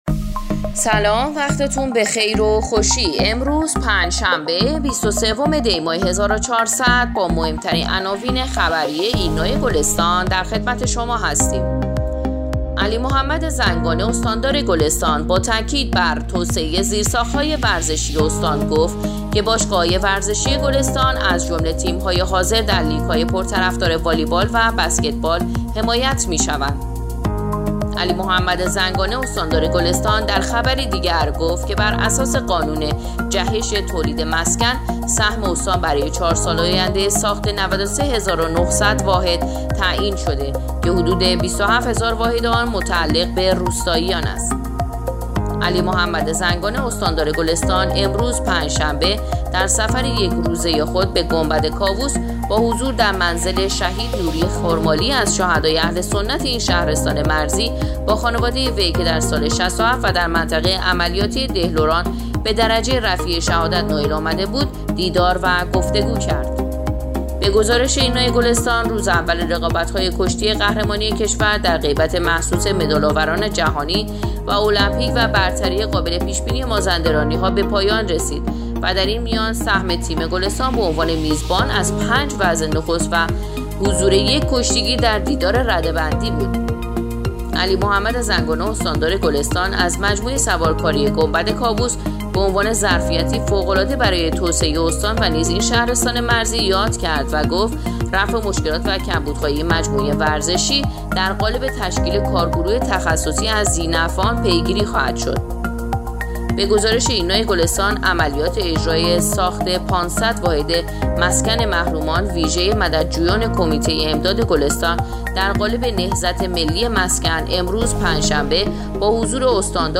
پادکست/ اخبار شبانگاهی بیست و سوم دی ماه ایرنا گلستان